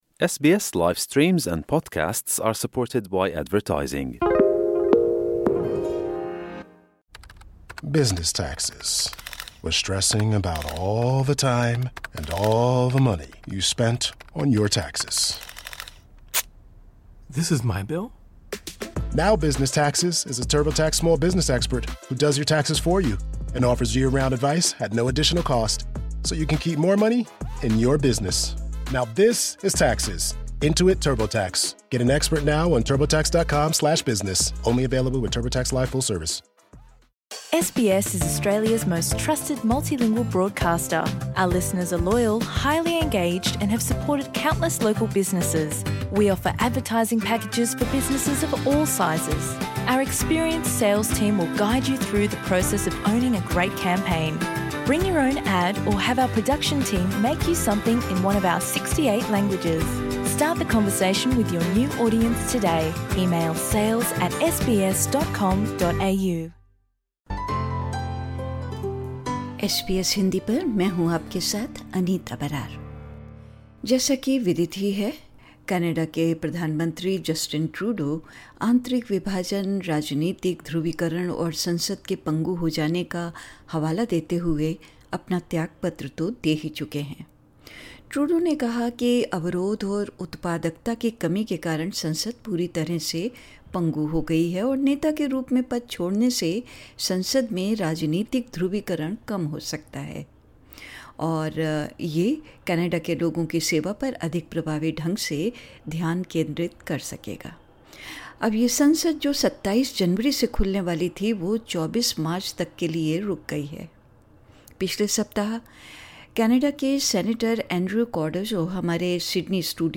Canadian Prime Minister Justin Trudeau announced his resignation, attributing it to internal divisions, political polarisation, and a stagnant Parliament. During a recent visit to SBS studio, Canadian Senator Andrew Cardozo addressed pressing issues, including Trump’s threats to impose tariffs, concerns about the future of Canadian identity, and the prospects for Canada's multiracial society.